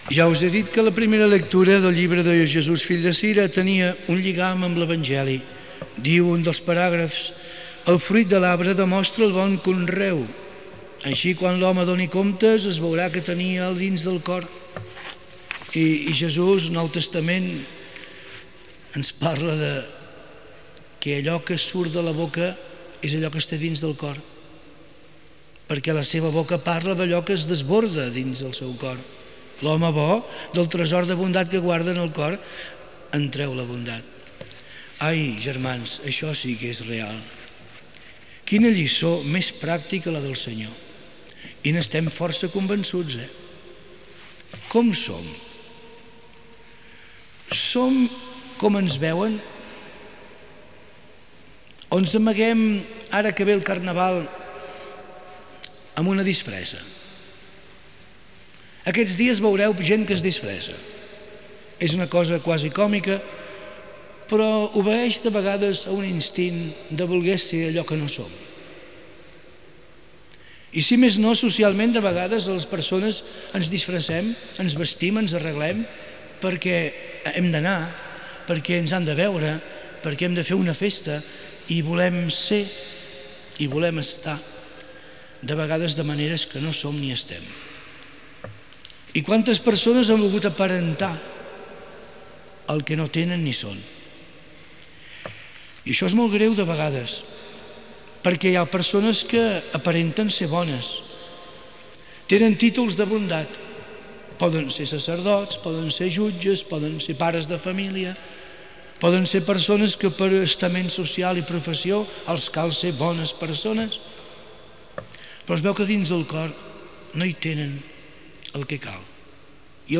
Corpus Oral de Registres (COR). REL1. Sermó dominical
Aquest document conté el text REL1, un "sermó dominical" que forma part del Corpus Oral de Registres (COR).